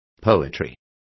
Complete with pronunciation of the translation of poetry.